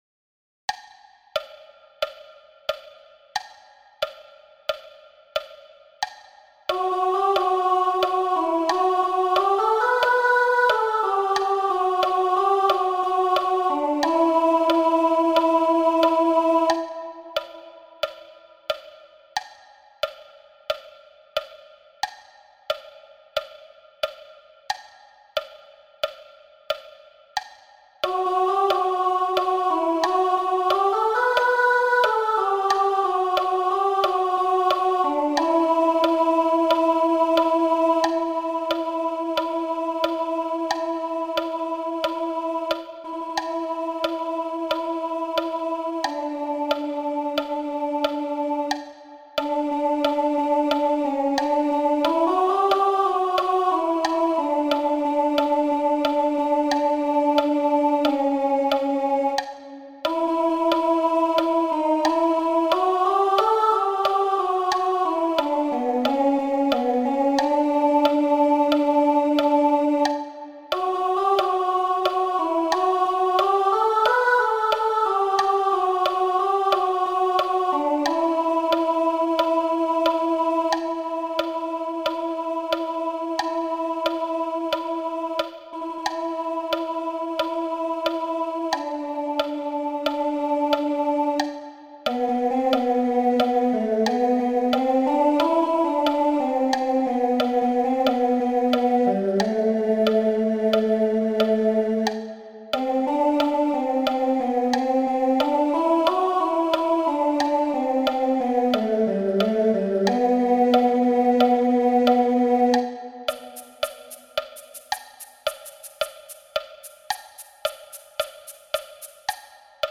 Subtítulo sonido MIDI con claqueta